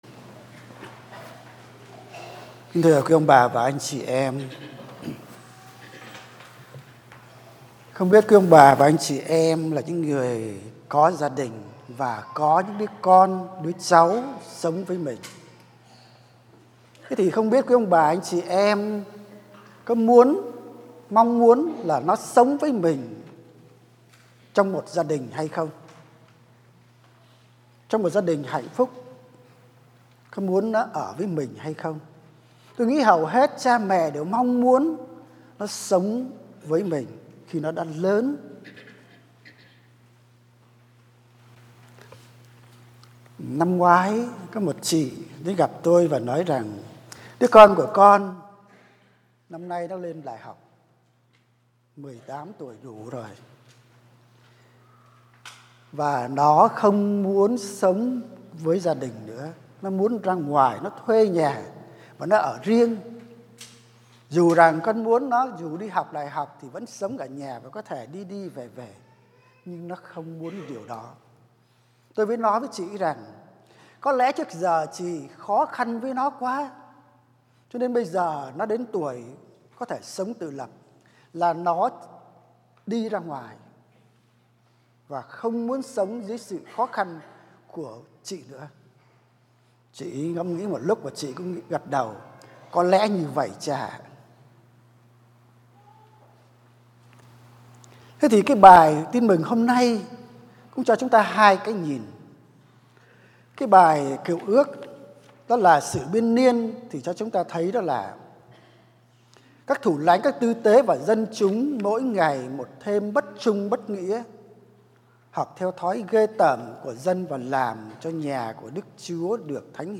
* Thể loại: Nghe giảng